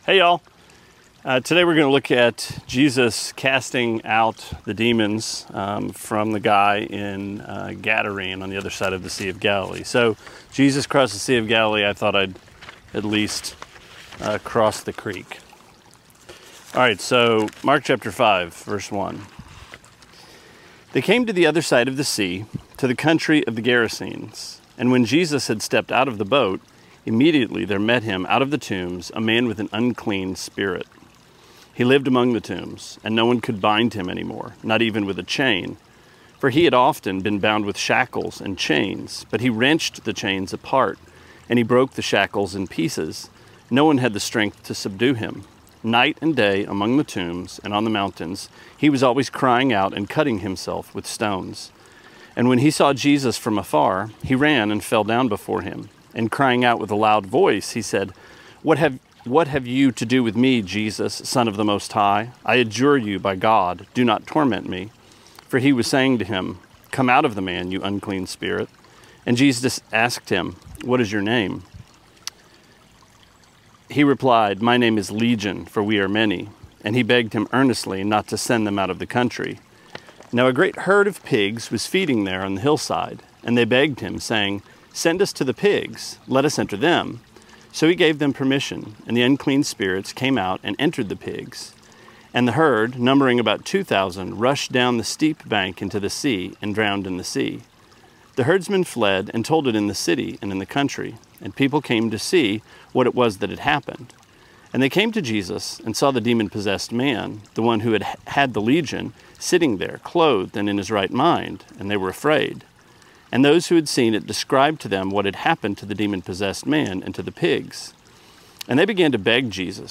Sermonette 7/2: Mark 5:1-20: D-Day!